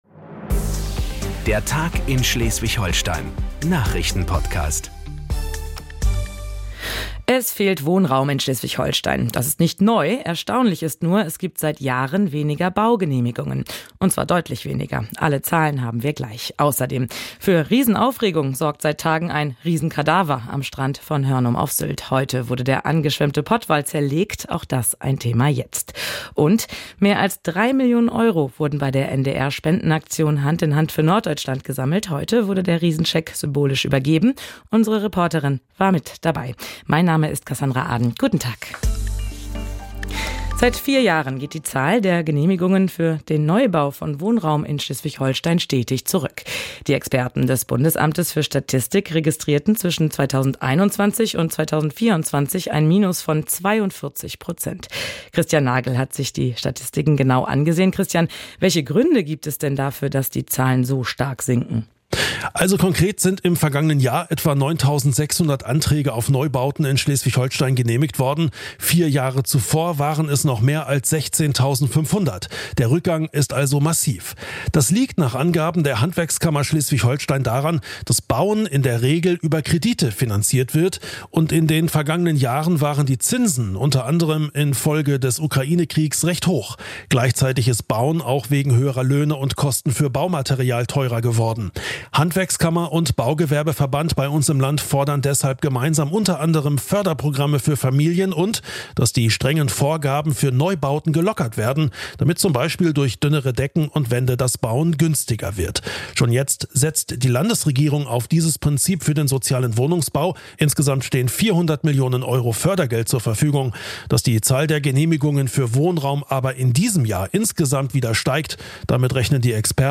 1 Der Tag in SH vom 27.11.2024 8:22 Play Pause 3h ago 8:22 Play Pause Später Spielen Später Spielen Listen Gefällt mir Geliked 8:22 Der Tag in Schleswig-Holstein - alles was wichtig ist für Schleswig-Holstein hören Sie im Nachrichtenpodcast von NDR Schleswig-Holstein. Wir fassen den Tag zusammen, ordnen ein und beleuchten Hintergründe.